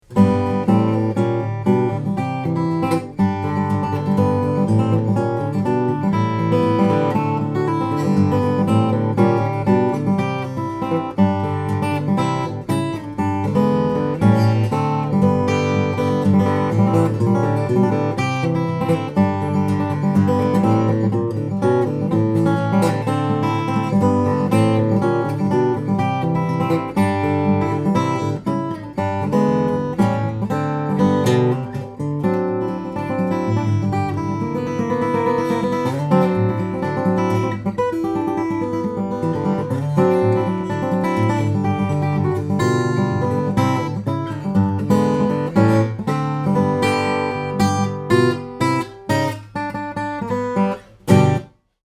The finely quartered Mahagony back and sides which gives it a nice warmth. Combined with a Spruce top, this one has a lovely overtone presence almost reminiscent of a Rosewood guitar.